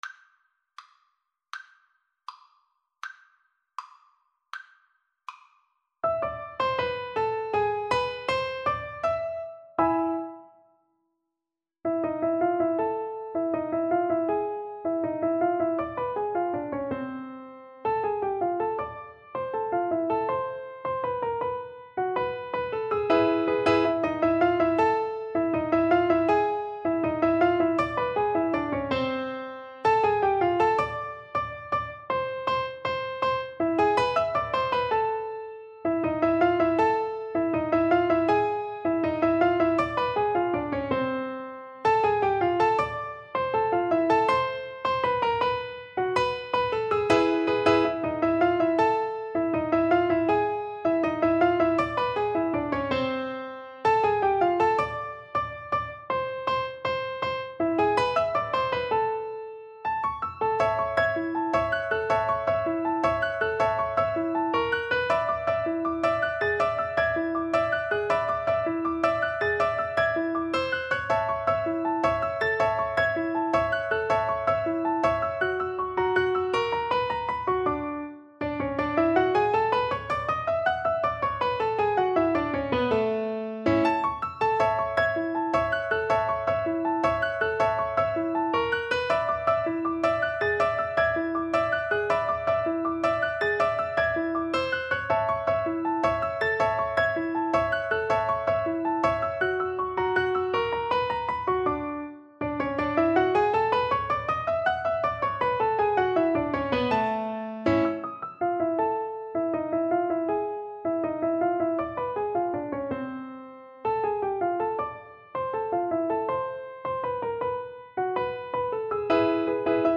Free Sheet music for Piano Four Hands (Piano Duet)
2/4 (View more 2/4 Music)
World (View more World Piano Duet Music)